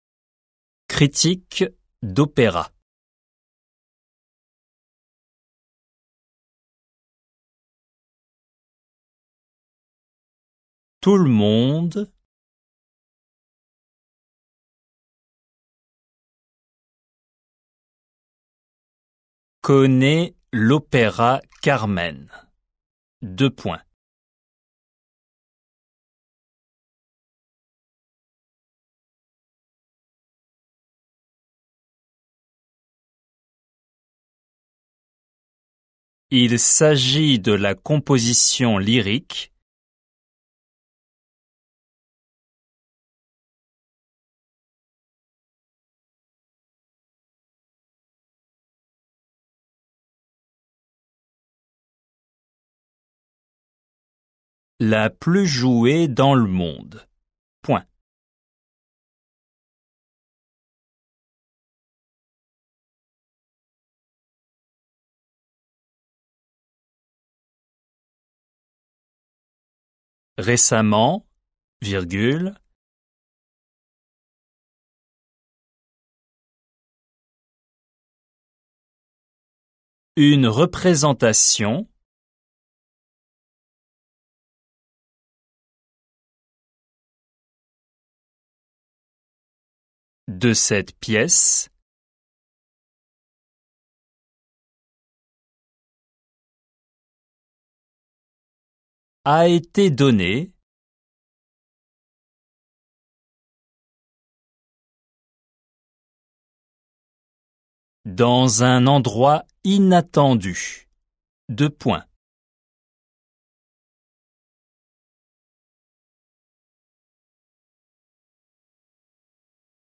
仏検2級―テキスト紹介ー２－デイクテー音声
p14_fr_avance_texte_dictee.mp3